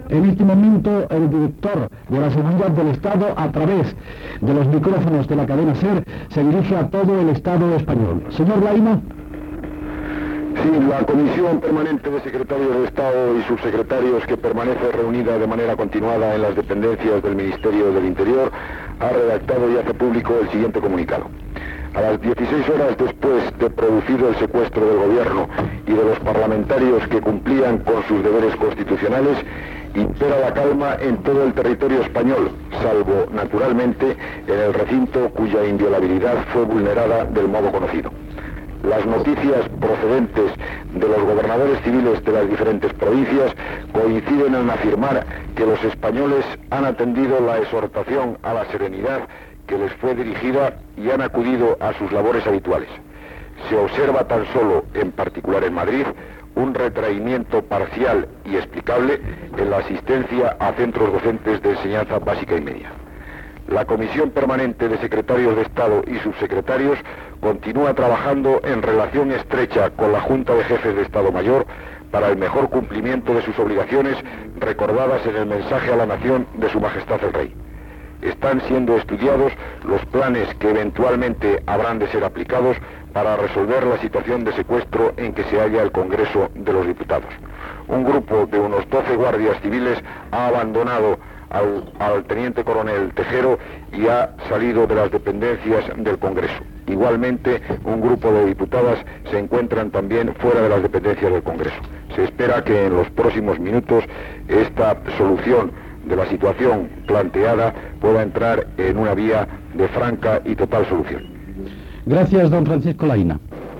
El director de Seguretat de l'Estat, Francisco Laína, fa públic un comunicat sobre la situació a les 10 del matí després de l'intent de cop d'estat
Informatiu
Extret del casset "La SER informa de pleno" publicat per la Cadena SER